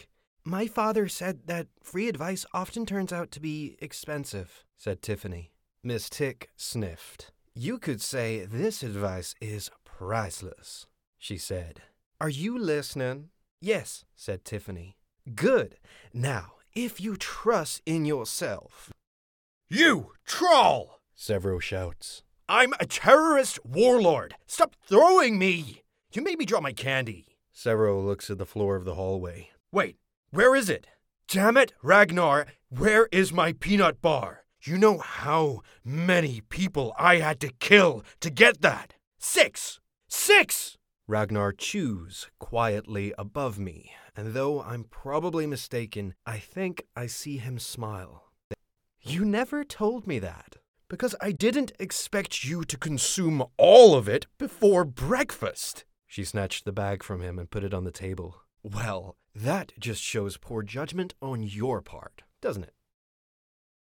US Reel
• Home Studio